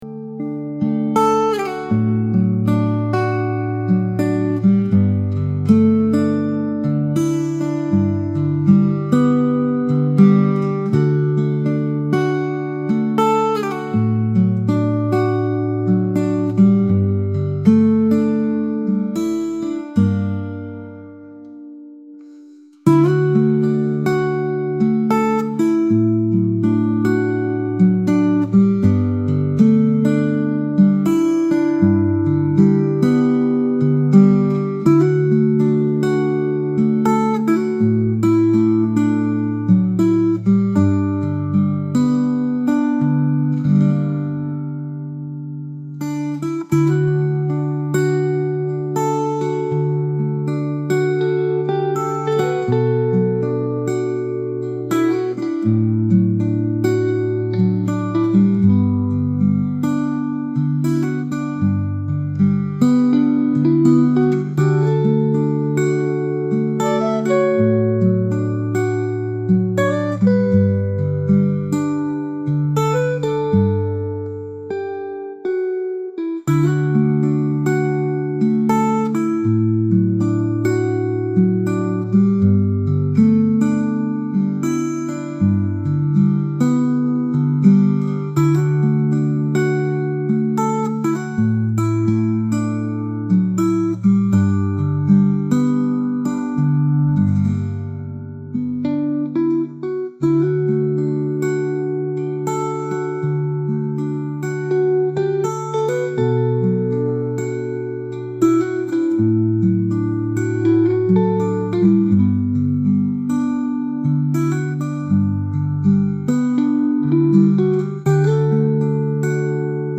雨音入り癒しのlo-fi/cillミュージック